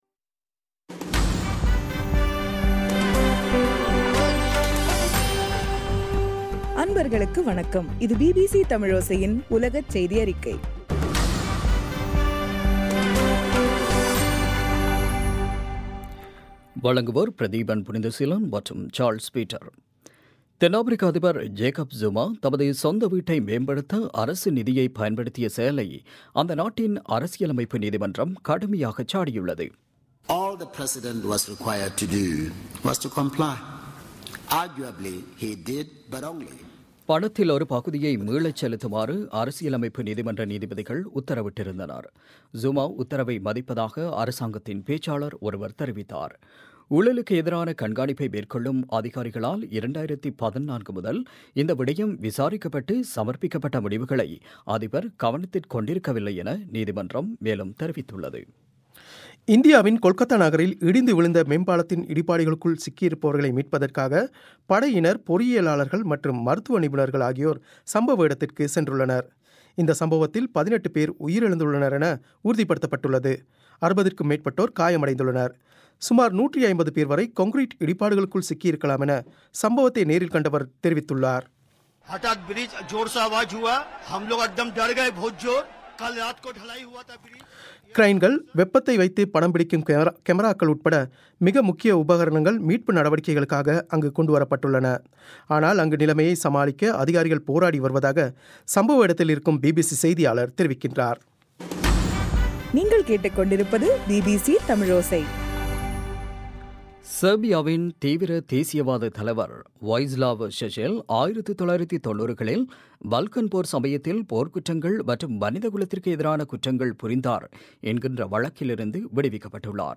மார்ச் 31 பிபிசியின் உலகச் செய்திகள்